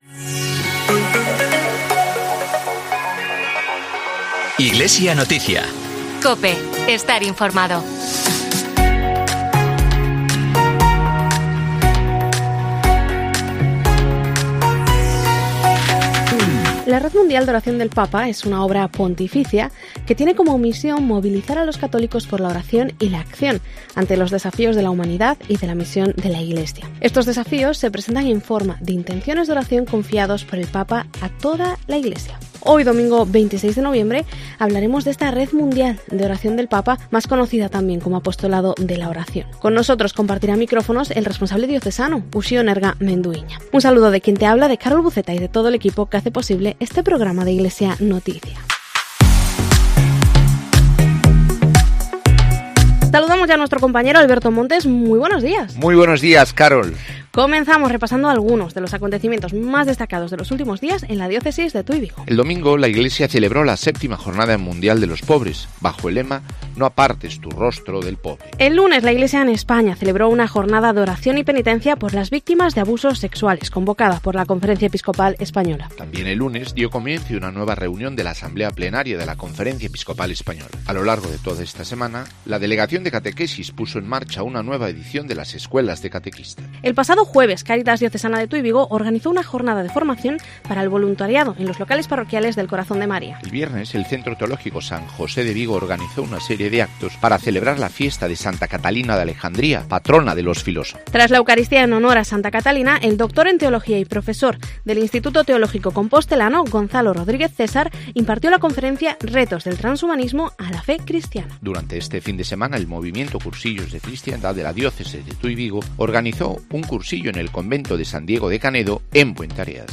AUDIO: Informativo diocesano